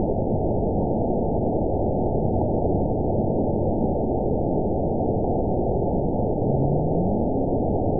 event 920065 date 02/21/24 time 00:29:49 GMT (1 year, 2 months ago) score 9.25 location TSS-AB05 detected by nrw target species NRW annotations +NRW Spectrogram: Frequency (kHz) vs. Time (s) audio not available .wav